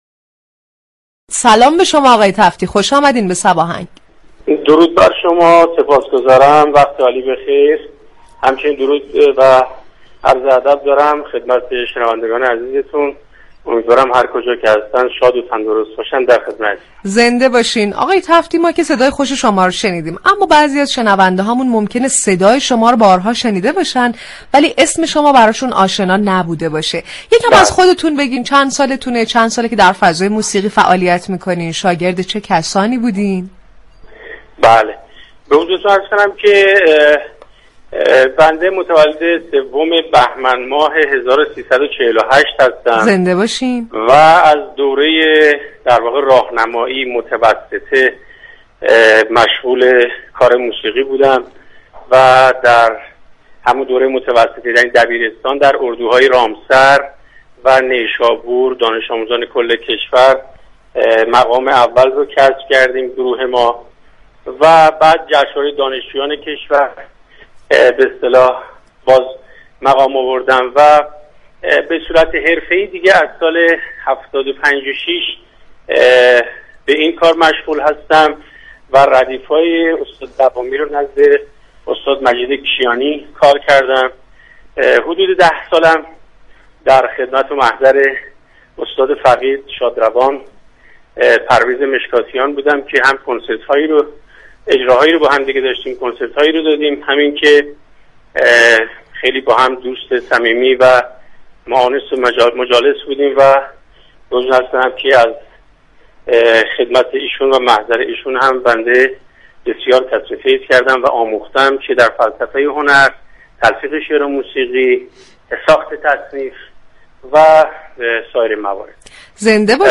امیر محمد تفتی خواننده سنتی در گفتگو با رادیو صبا از ویژگی های موسیقی ایرانی گفت